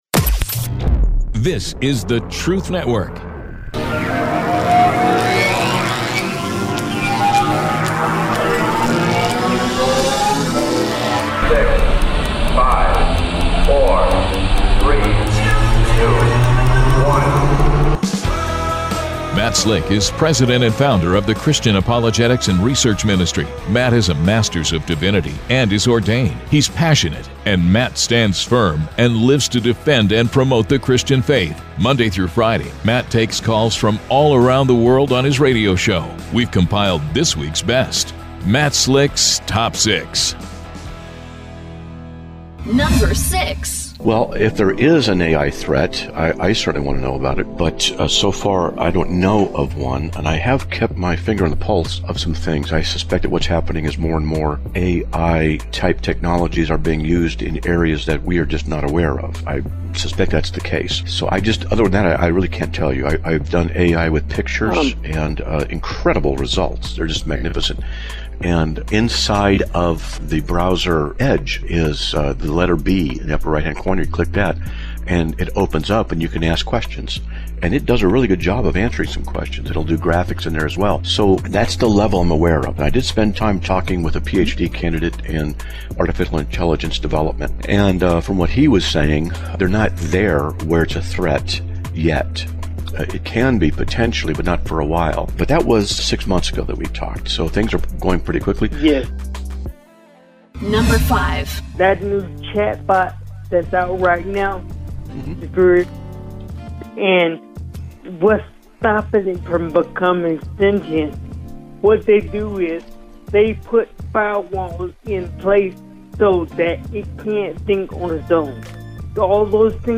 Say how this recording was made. The sound bites are from Roy Rogers